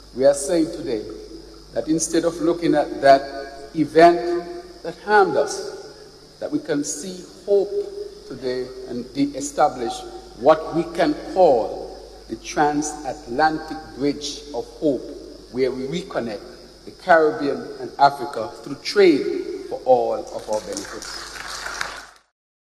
In an address at a high-level forum alongside African heads of state, the Prime Minister of Saint Kitts and Nevis, the Hon. Dr. Terrance Drew, made a call for deepened economic, cultural, and political ties between the African continent and its recognized sixth region of the Caribbean.